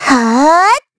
Xerah-Vox_Casting2_kr.wav